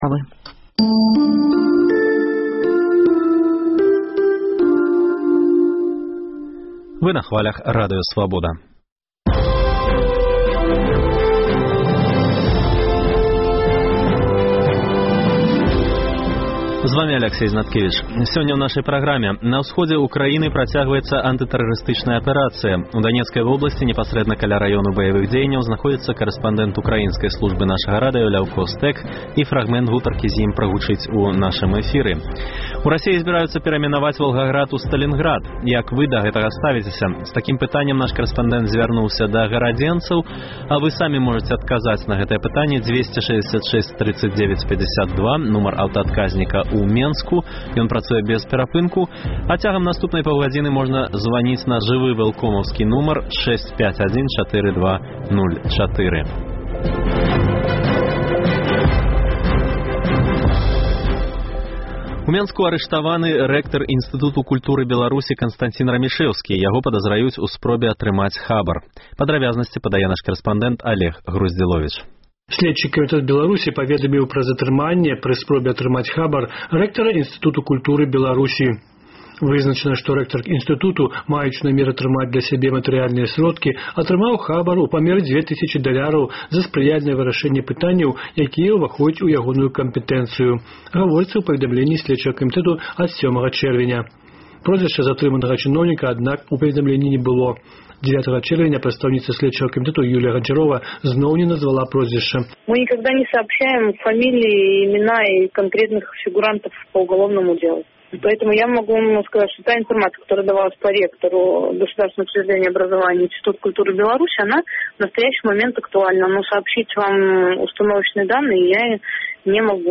Гутарка зь ім прагучыць у нашым эфіры. Адмысловую інтрыгу сёлета мае віцебскі фэстываль культураў «Славянскі базар».